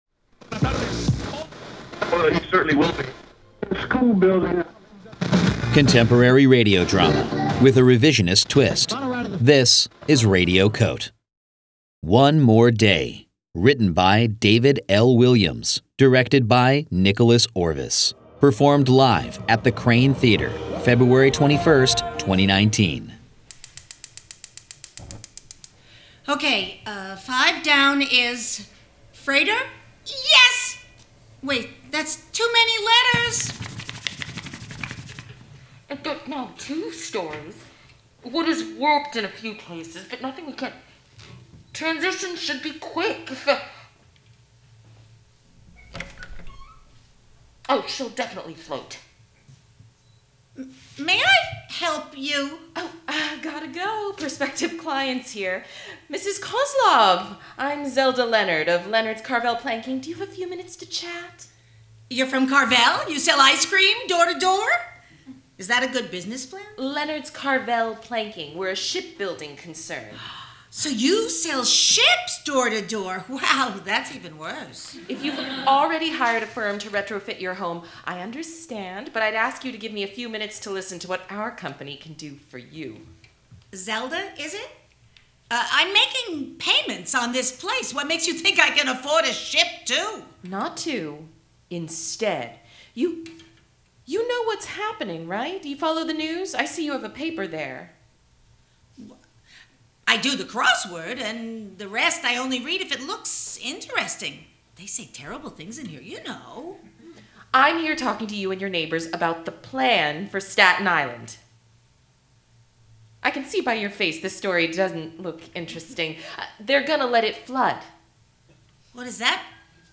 performed for Radio COTE: 24-hour Newsroom in the FRIGID Festival, February 21, 2019